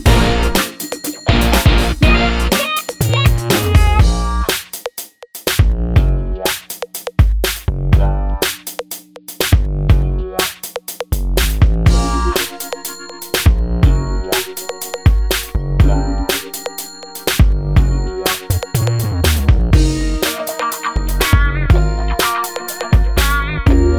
No Backing Vocals Soundtracks 3:03 Buy £1.50